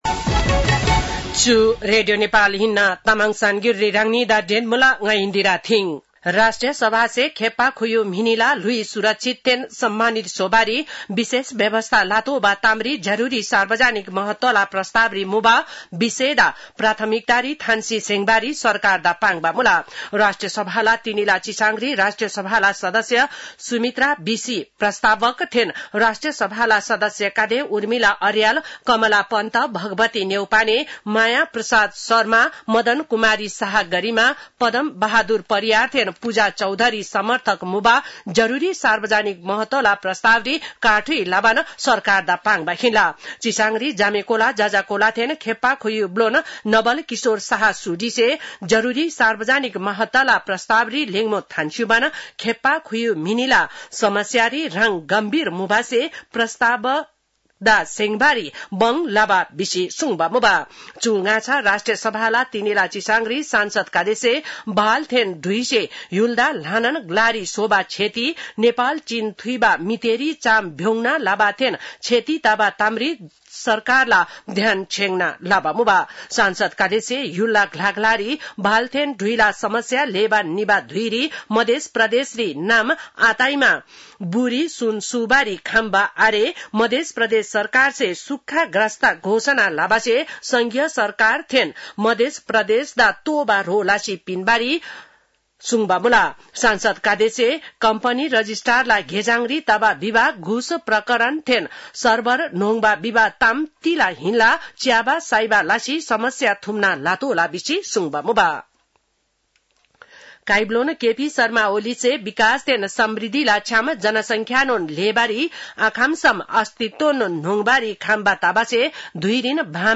तामाङ भाषाको समाचार : २७ असार , २०८२
Tamang-news-3-27.mp3